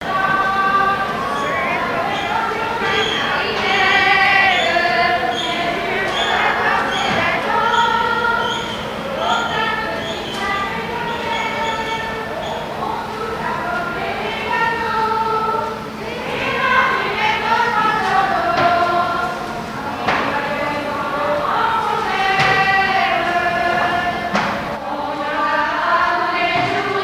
Enregistrement à l'usine de conserves de sardines Amieux
Genre strophique
Chansons traditionnelles
Pièce musicale inédite